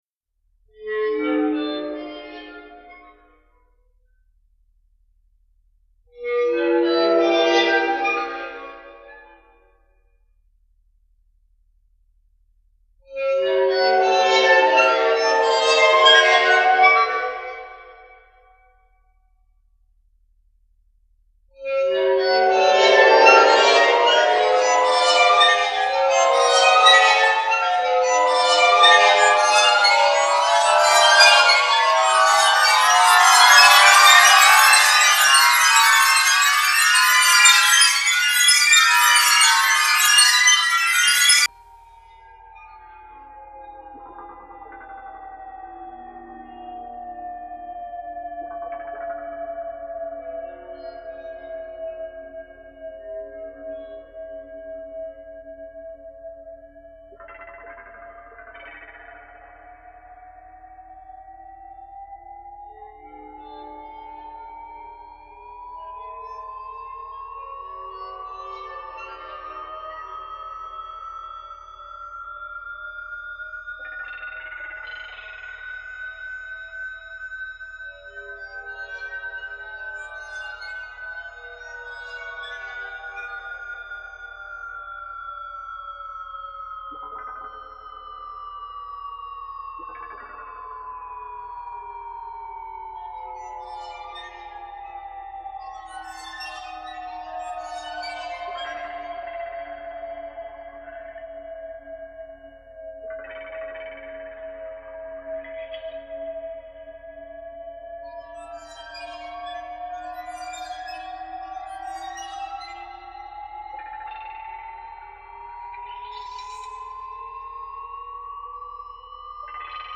Синтезатор "Kurzweil", 1996, 2008 гг.